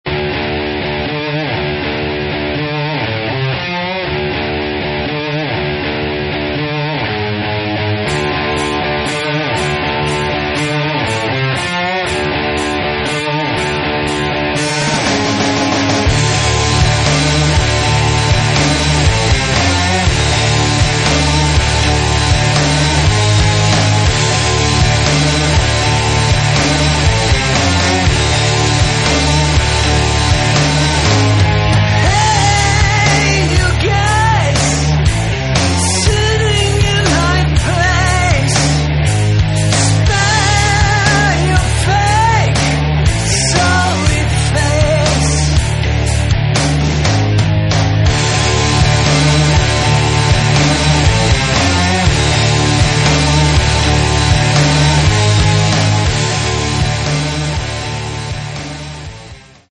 Рок
соло-гитара